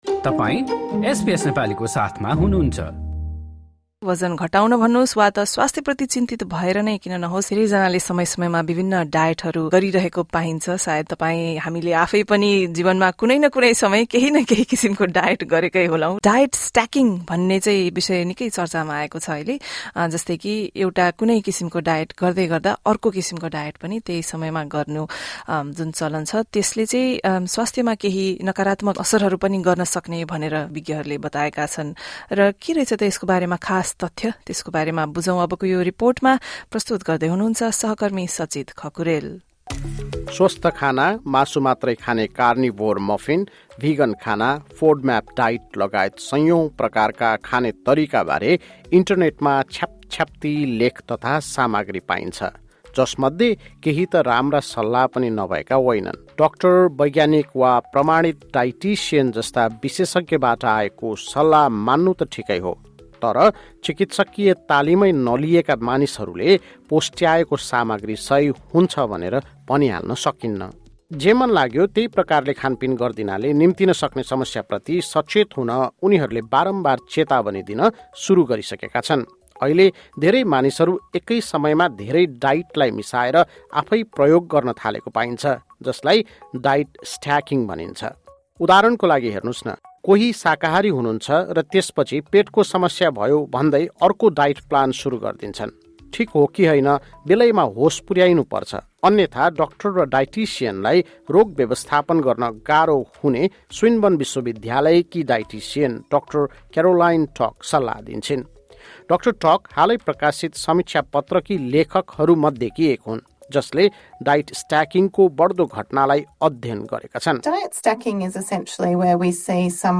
तर एकै समयमा धेरै डाइटहरू अपनाउँदा अप्रत्याशित असर पर्न सक्ने विज्ञहरूले चेतावनी दिएका छन्। एक रिपोर्ट।